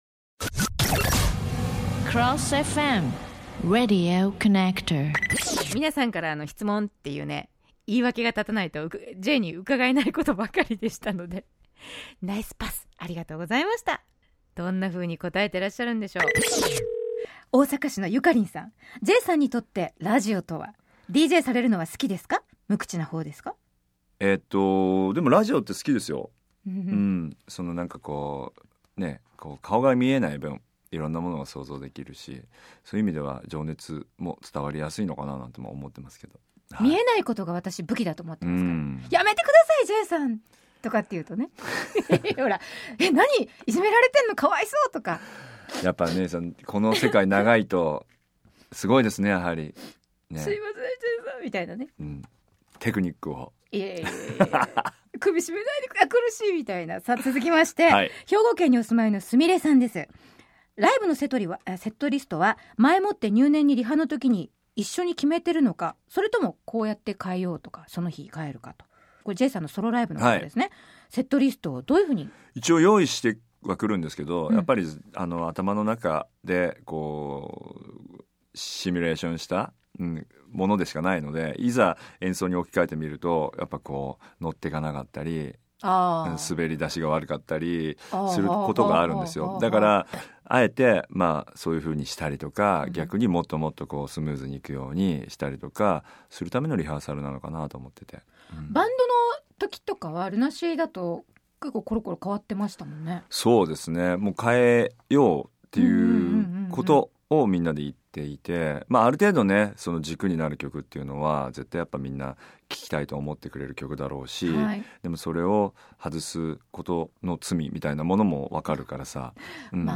貴重なベルボ（ベルベットボイス）降臨タイムです。